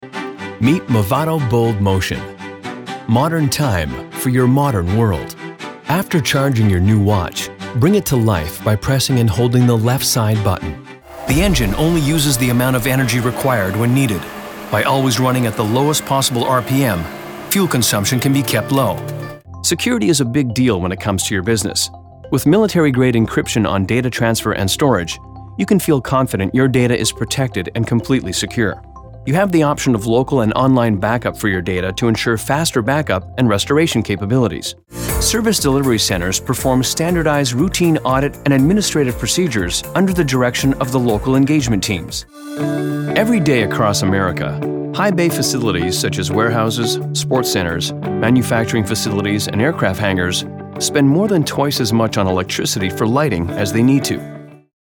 Young Adult
Middle Aged
18+ yrs full time Voice Actor with a Pro home studio and Source Connect.